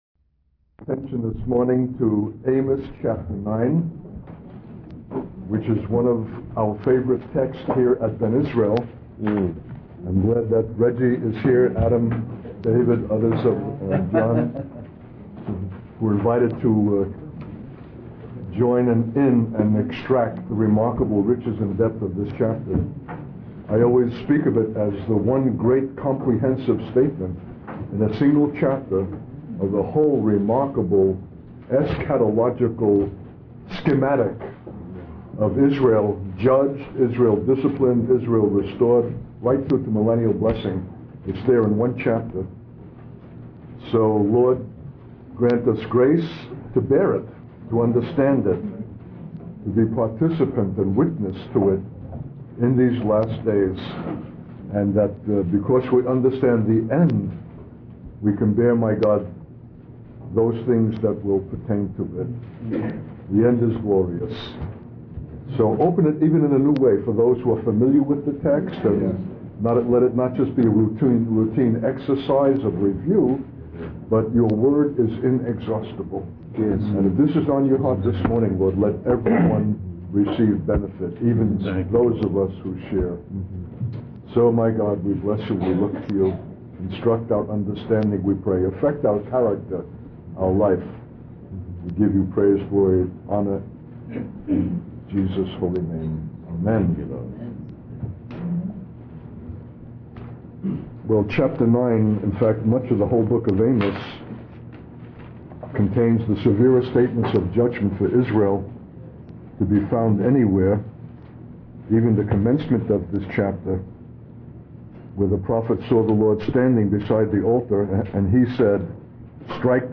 In this sermon, the speaker reflects on his 14-month journey through different nations, carrying only what he could on his back.